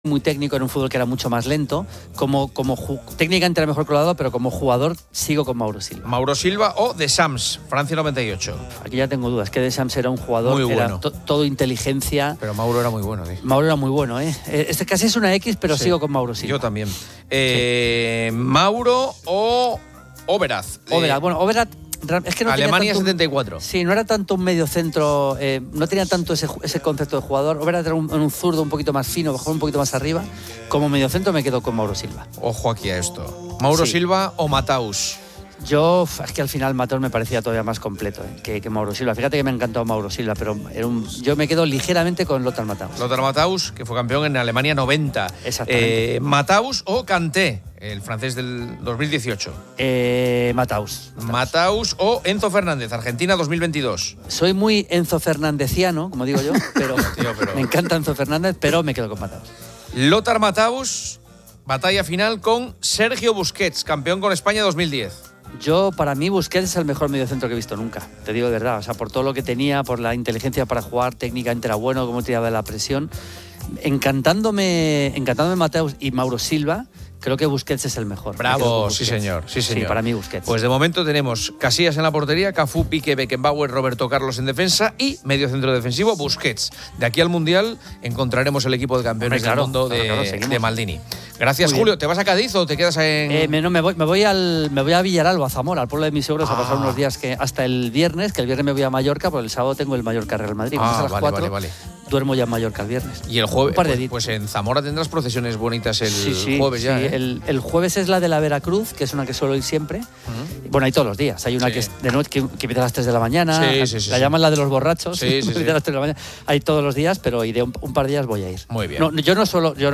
Se insiste en concienciar a la sociedad para no automedicarse, completar tratamientos y desechar correctamente los antibióticos en farmacias, así como en una prescripción más responsable por parte de médicos y veterinarios. La conversación también aborda soluciones innovadoras, como la aplicación de bacteriófagos para combatir bacterias, y el hallazgo de un gen de panresistencia diseminado globalmente que otorga inmunidad a familias enteras de antibióticos.